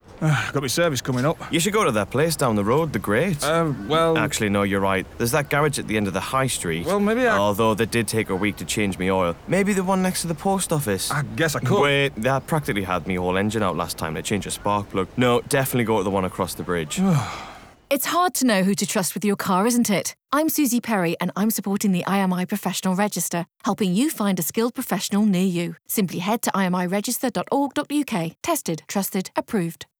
Radio Adverts
Listen to the radio adverts featuring Suzi Perry .